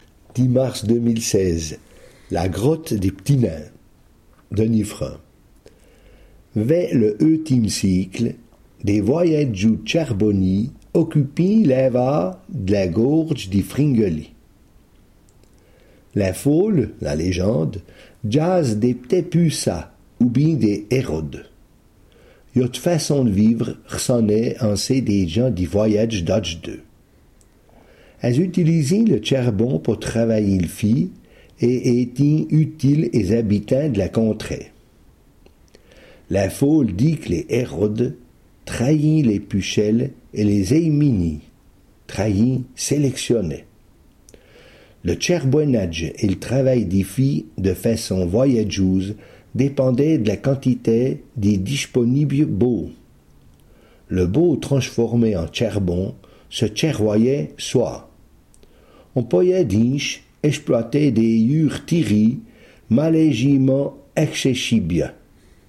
Ecouter le r�sum� en patois